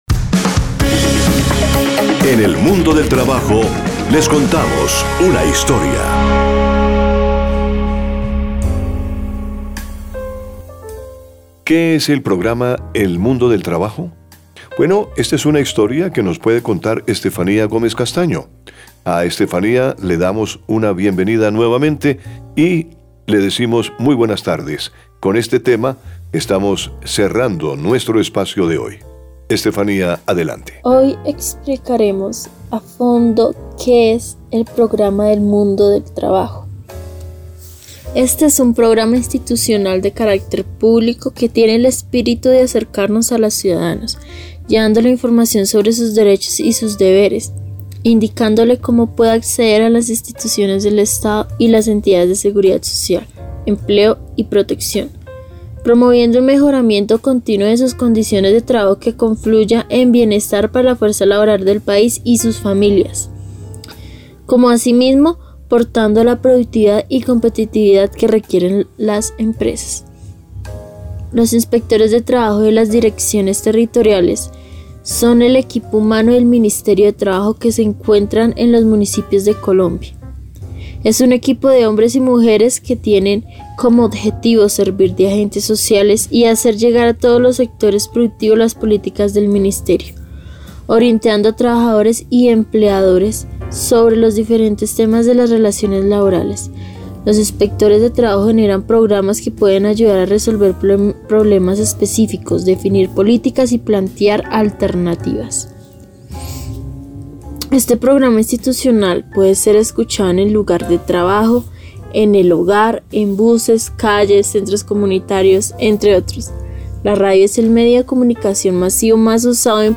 Narradora: